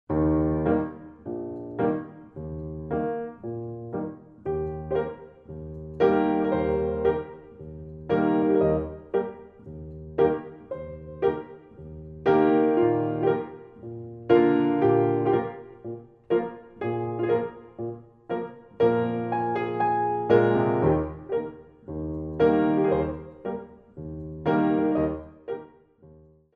Degage (faster repeat)